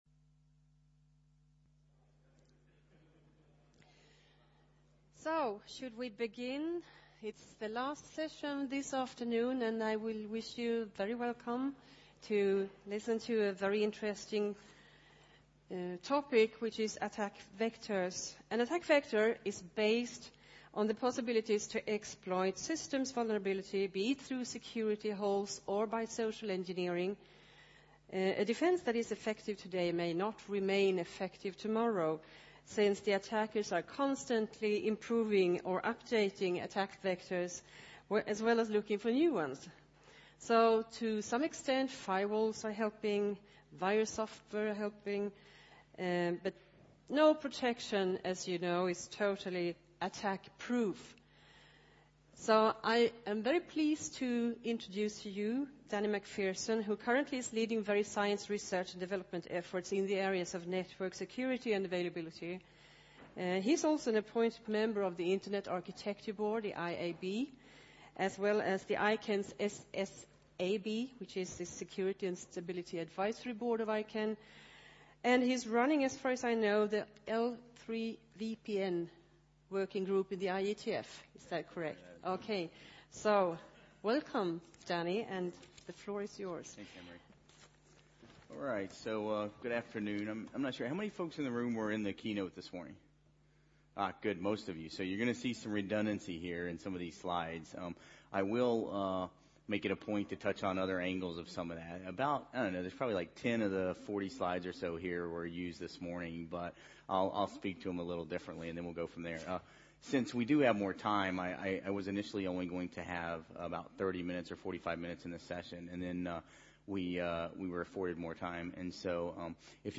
Attack Vectors Plats: Kongresshall C Datum: 2010-10-26 Tid: 15:30-17:00 An attack vector is based on the possibilities to exploit system vulnerabilities, be it through security holes or by social engineering.